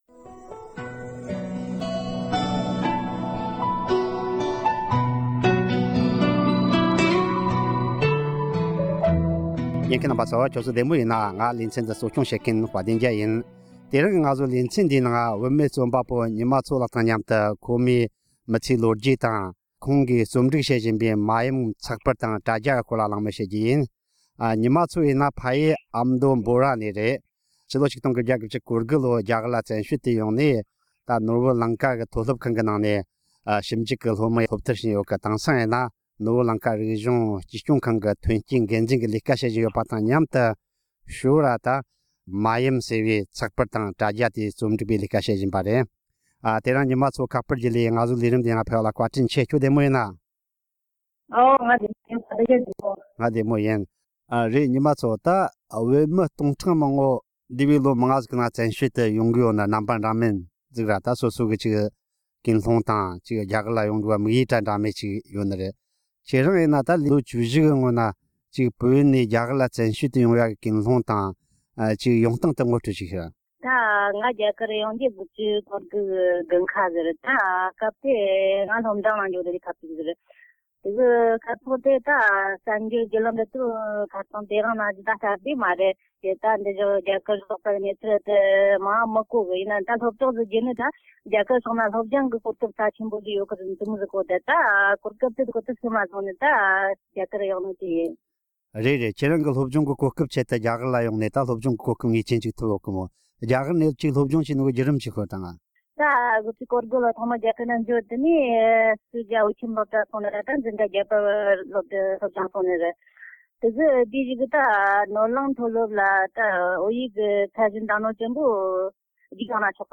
མཉམ་དུ་གླེང་མོལ་ཞུས་པ།